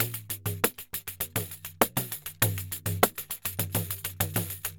Pandeiro 1_Samba 100_2.wav